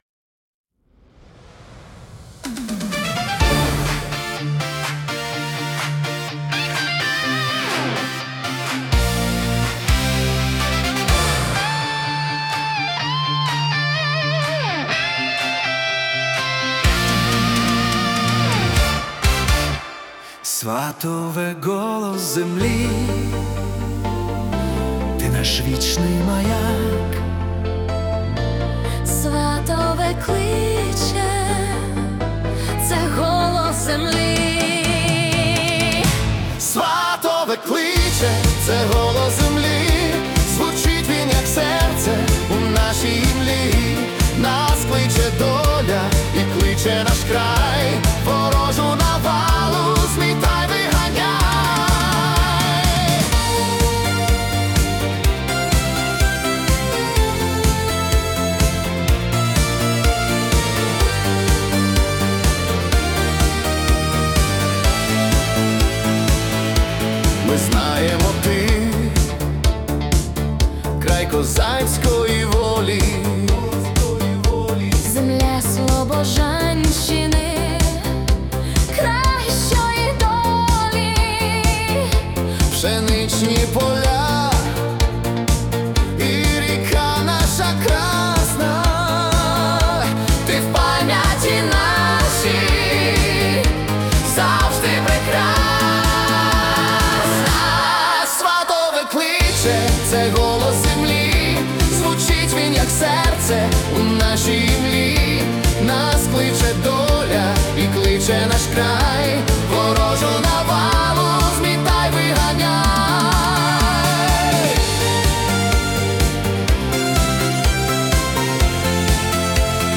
Patriotic Disco / Anthem